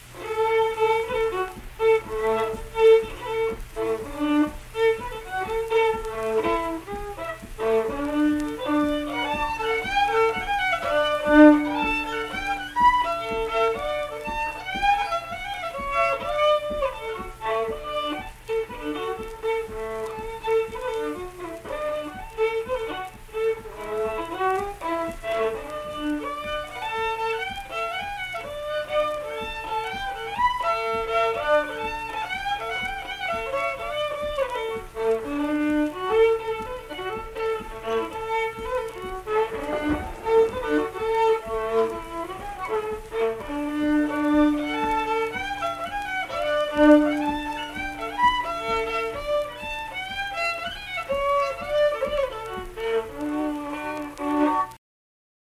(Forked Deer) - West Virginia Folk Music | WVU Libraries
Unaccompanied fiddle performance
Instrumental Music
Fiddle